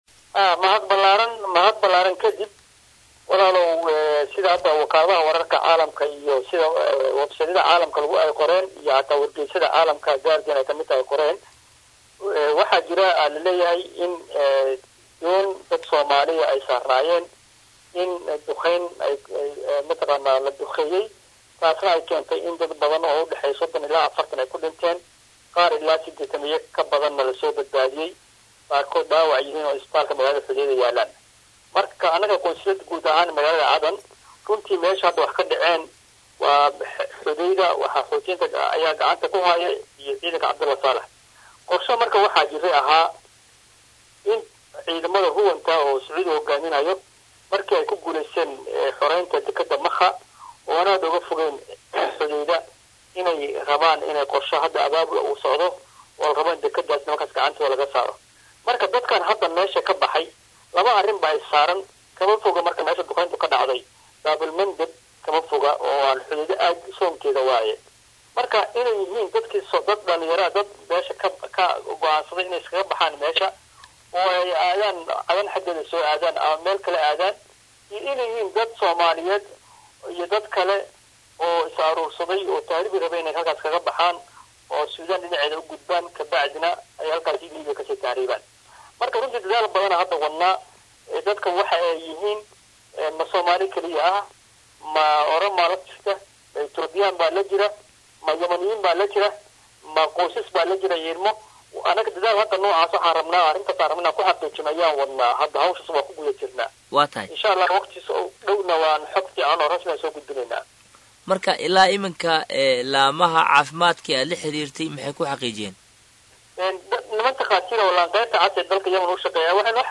Qunsulka waxa uu ka hadlay dadaalka ay ugu jiraan soo daad gureynta Soomaalida ku xaniban gudaha dalka Yemen. Halkan ka dhageyso wareysiga
waraysi-Qunsulka-guud-ee-Soomaaliya-ufadhiya-Magaalada-Cadan-Axmad-Cabdi-Xasan-Axmad-Sudaani-.mp3